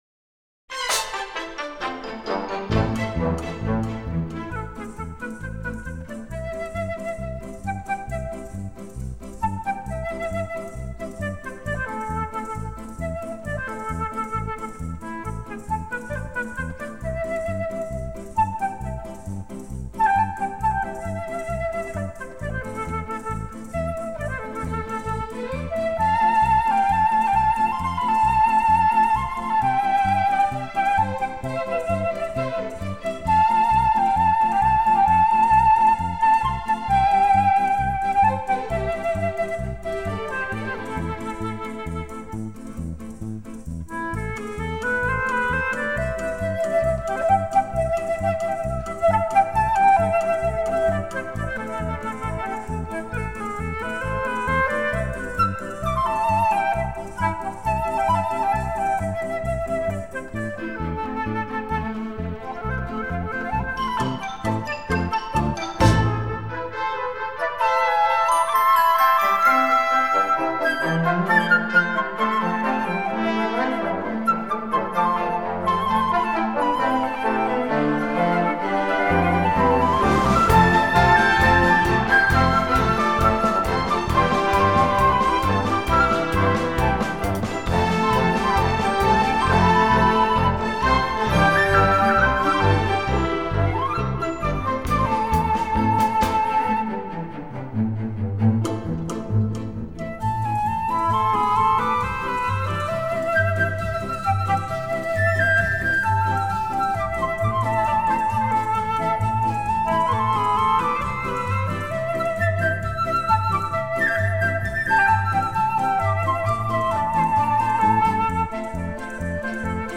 他吹奏出的独特的音色、高深的音乐修养、独到的鉴赏力和令人眼花缭乱的演奏技巧使他成为最受尊敬和最出色的艺术家。